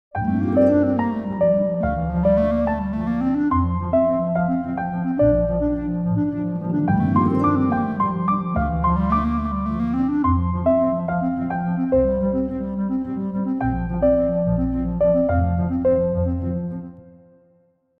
Piano + ligne de basse + glissando de harpe.
2. Ajout d’un leitmotiv pour donner du mouvement :
Pour ce phrasé rapide en contrechant, j’opte pour une clarinette assez fluide.
avec_clarinette.mp3